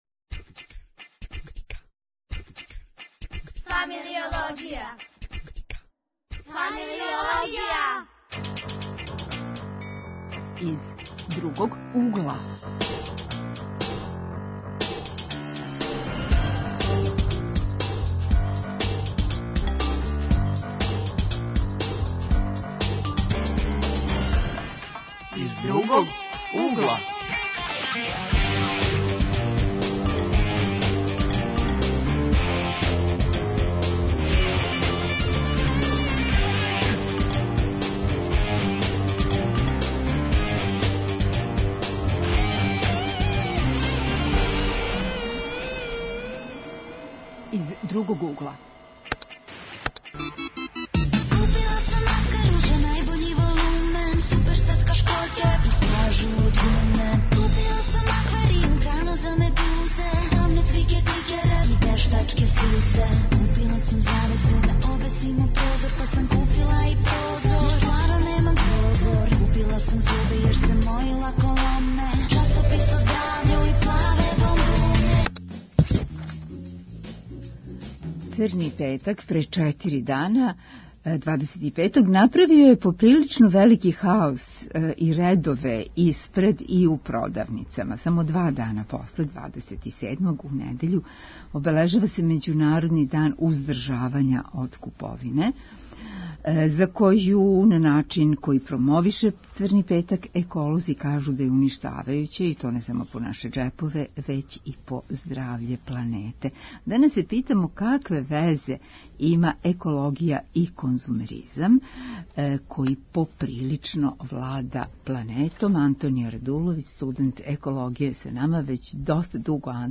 Гости су студенти.